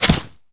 bullet_impact_snow_02.wav